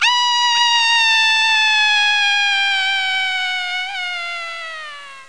fscream3.mp3